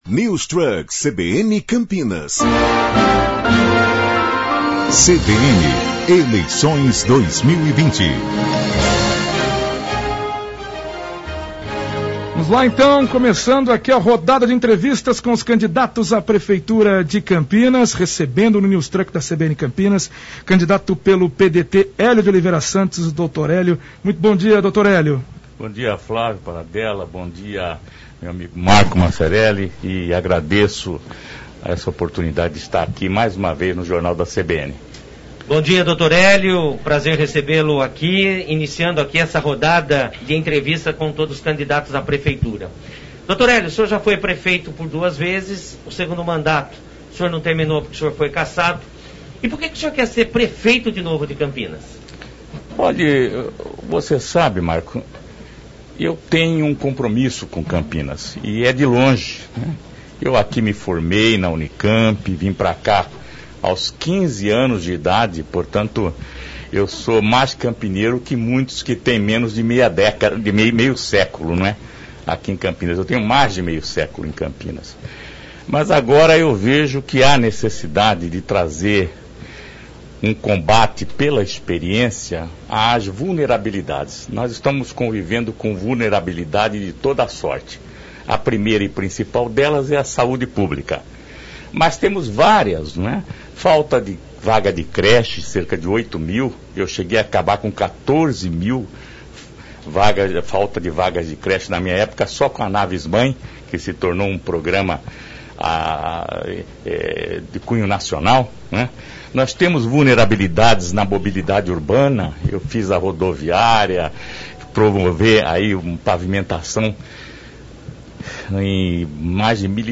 Entrevista com o candidato Dr. Hélio, do PDT - Parte 01 - CBN Campinas 99,1 FM
Candidato a Prefeito de Campinas , Dr. Hélio participa da rodada de entrevistas da CBN Campinas.